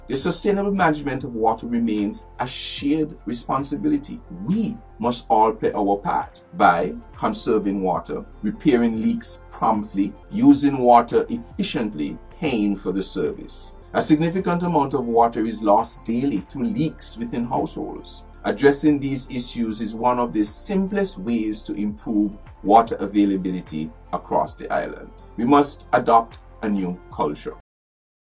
Recent investments were highlighted but it was stressed that infrastructure alone is not enough. Minister responsible for water services-St. Kitts, the Hon Konris Maynard, made this statement: